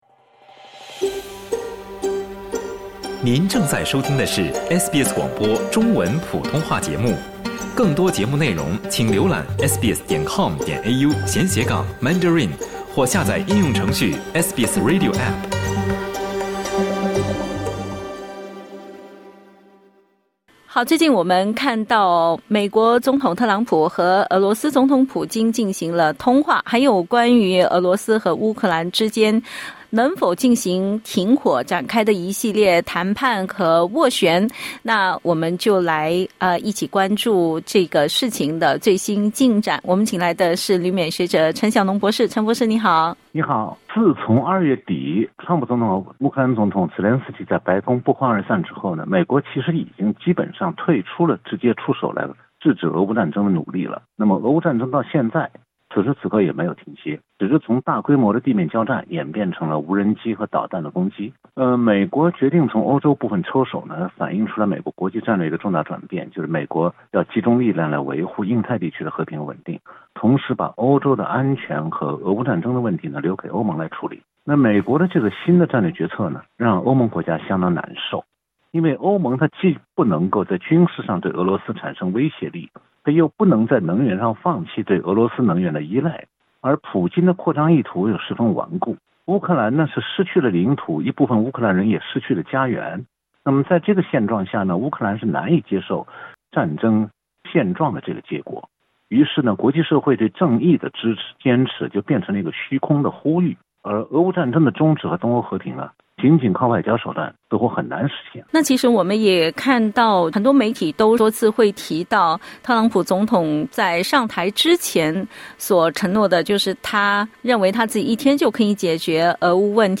点击音频收听详细内容 采访内容仅为嘉宾观点 欢迎下载应用程序SBS Audio，订阅Mandarin。